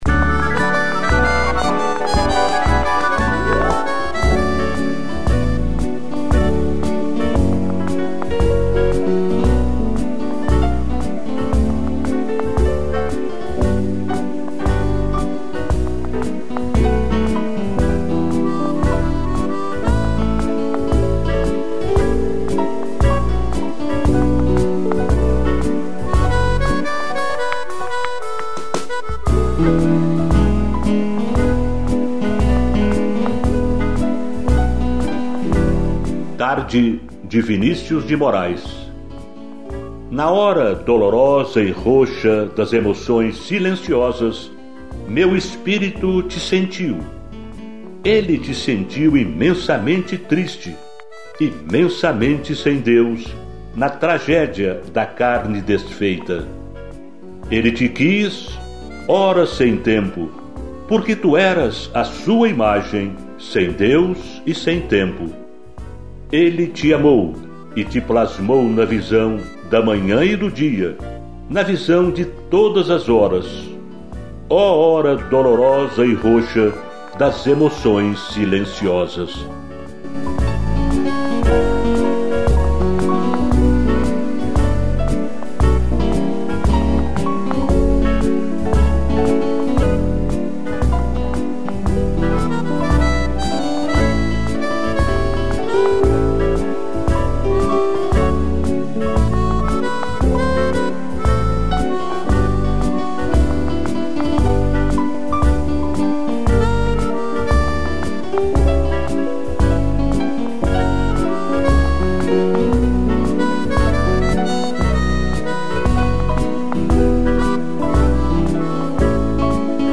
piano e flauta